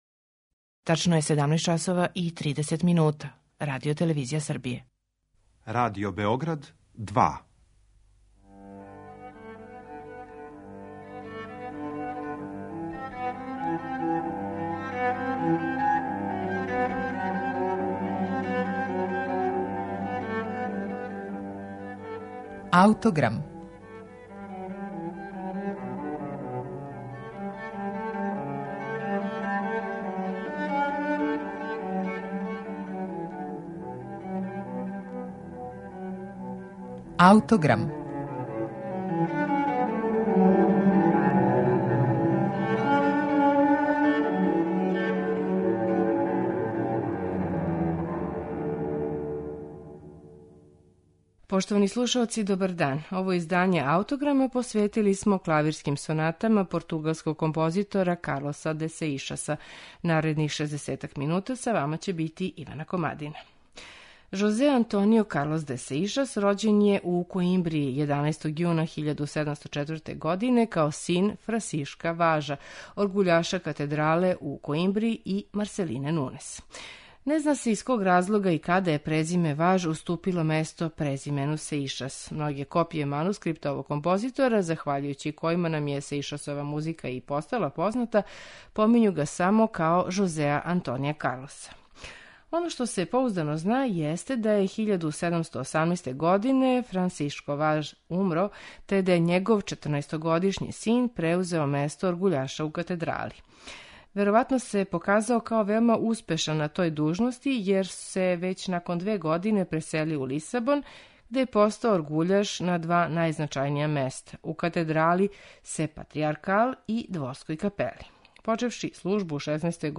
За данашњи Аутограм издвојили смо најбоље примере Сеишасовог композиторског умећа, његове сонате за чембало.